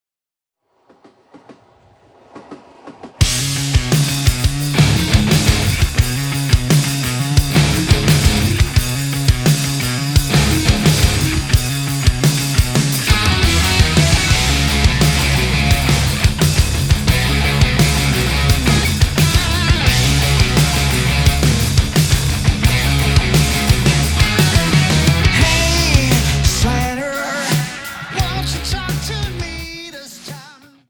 Swedish melodic hard rockers
GENRE: Hard Rock